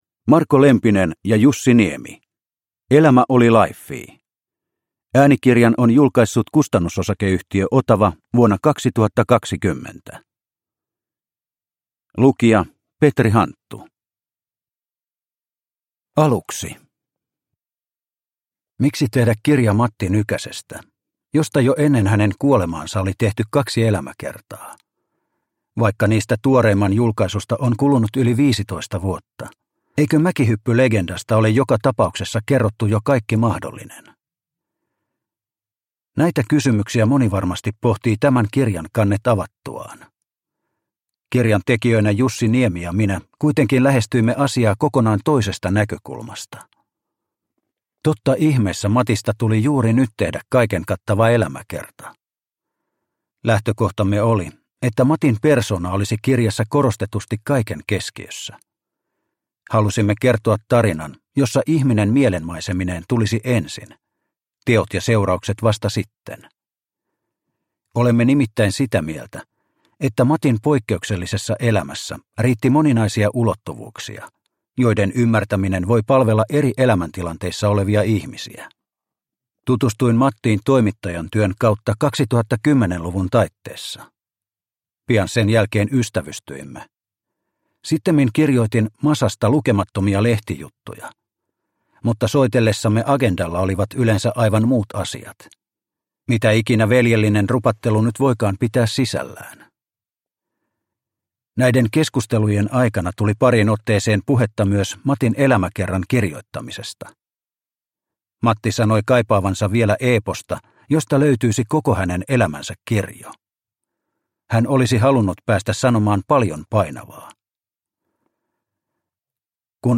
Elämä oli laiffii – Ljudbok – Laddas ner